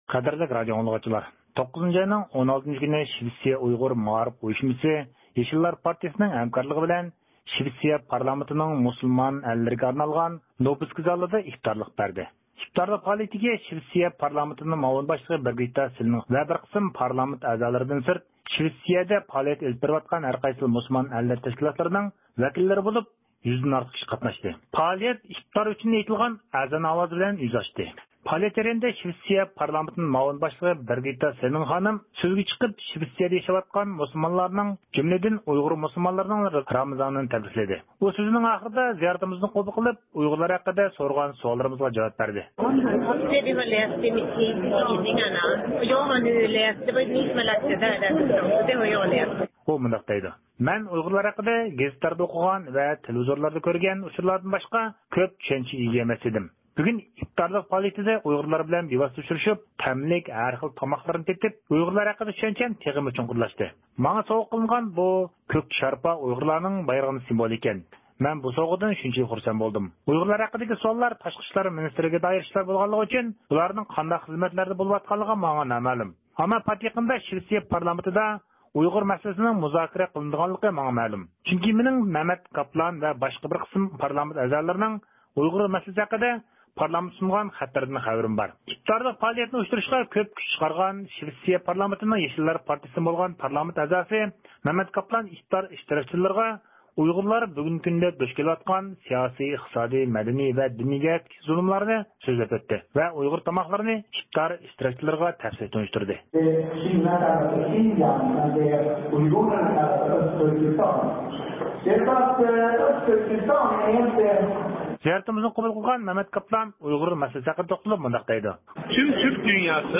9 – ئاينىڭ 16 – كۈنى شىۋېتسىيە ئۇيغۇر مائارىپ ئۇيۇشمىسى يېشىللار پارتىيىسىنىڭ ھەمكارلىقى بىلەن شىۋېتسىيە پارلامېنتىنىڭ مۇسۇلمان ئەللىرىگە ئاجرىتىلغان نۇبىسكا زالىدا ئىپتارلىق بەردى.
پائالىيەت جەريانىدا شىۋېتسىيە پارلامېنتىنىڭ مۇئاۋىن باشلىقى بېرگىتتا سېللېن خانىم سۆزگە چىقىپ شىۋېتسىيىدە ياشاۋاتقان مۇسۇلمانلارنىڭ جۈملىدىن ئۇيغۇر مۇسۇلمانلىرىنىڭ رامىزانىنى تەبرىكلىدى.
ئىپتارلىق پائالىيەتنى ئۇيۇشتۇرۇشقا كۆپ كۈچ چىقارغان شىۋېتسىيە پارلامېنتىنىڭ يېشىللار پارتىيىسىدىن بولغان پارلامېنت ئەزاسى مەمەت قاپلان ئىپتار ئىشتىراكچىلىرىغا ئۇيغۇرلار بۈگۈنكى كۈندە دۇچ كىلىۋاتقان سىياسى، ئىقتىسادى، مەدەنى ۋە دىنىي جەھەتتىكى زۇلۇملارنى سۆزلەپ ئۆتتى ۋە ئۇيغۇر تاماقلىرىنى ئىپتار ئىشتىراكچىلىرىغا تەپسىلىي تونۇشتۇردى.